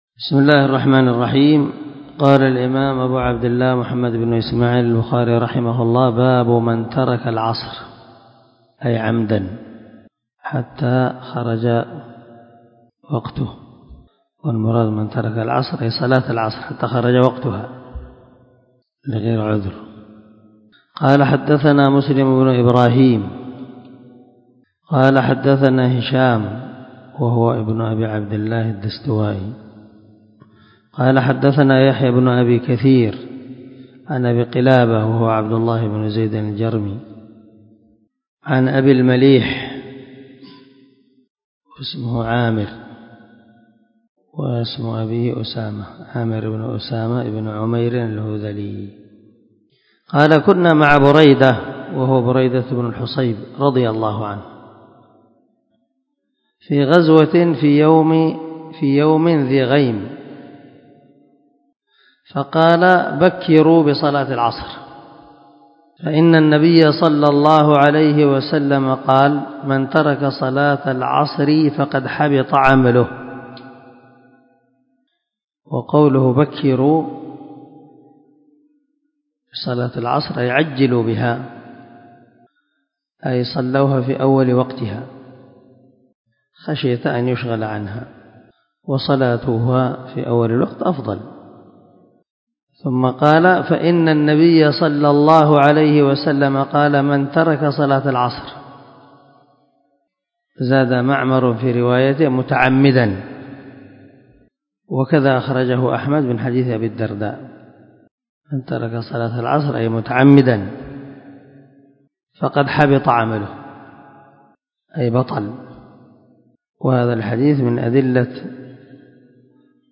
390الدرس 20 من شرح كتاب مواقيت الصلاة حديث رقم (553 ) من صحيح البخاري